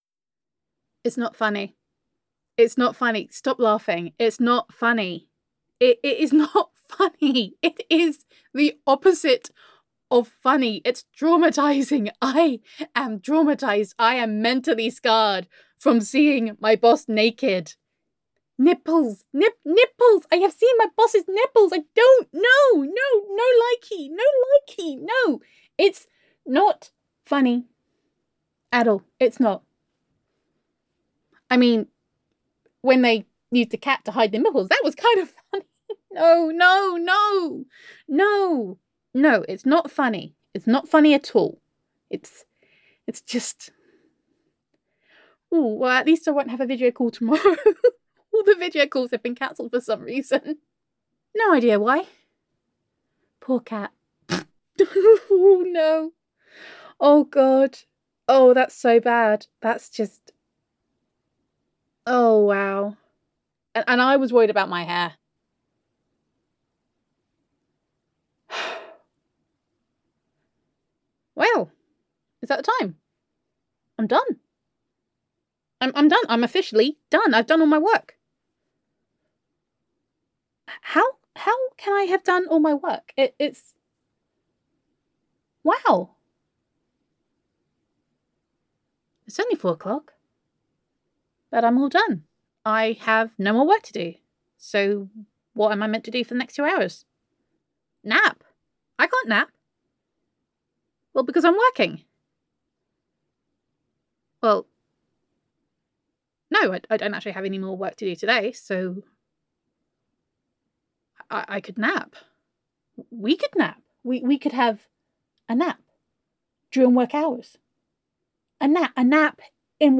[F4A] Day One - Nap Time [Girlfriend Roleplay][Self Quarantine][Domestic Bliss][Gender Neutral][Self-Quarantine With Honey]